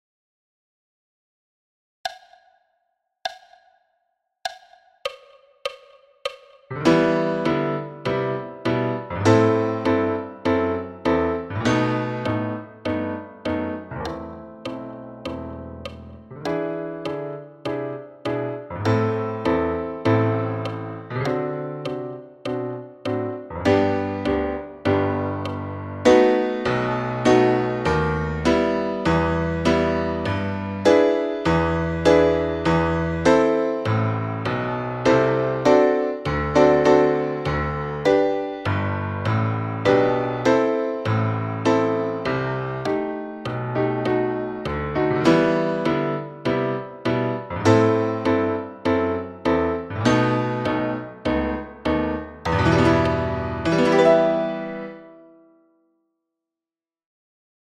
Etude n°5 – Londeix – piano solo à 100 bpm